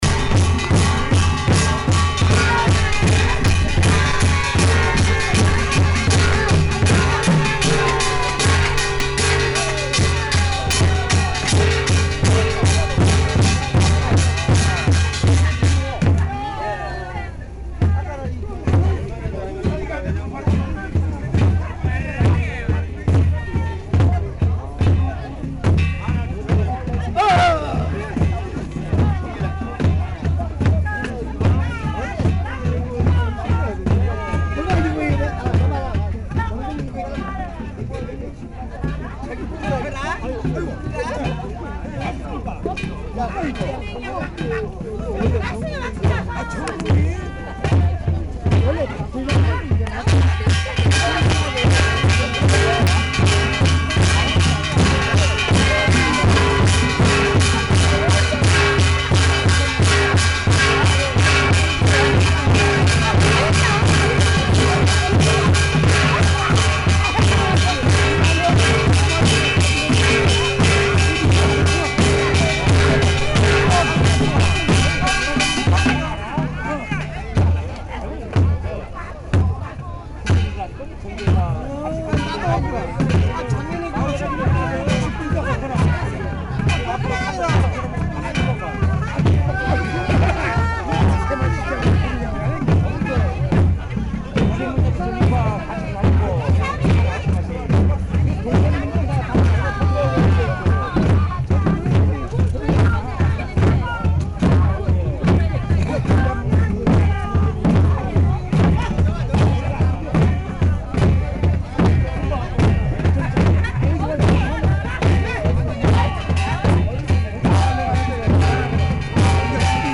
Tags: South Korea adjumma traditional